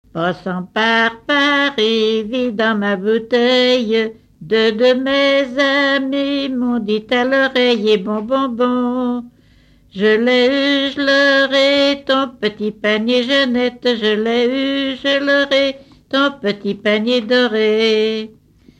circonstance : bachique
Pièce musicale inédite